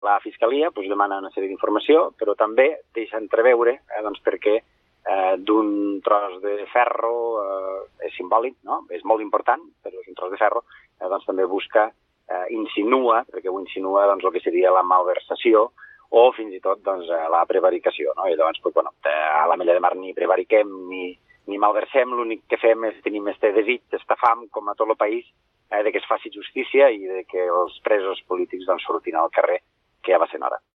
Per això, l’alcalde és contundent deixant clar que a l’Ametlla de Mar ni es malversa ni es prevarica. Són declaracions a Catalunya Ràdio.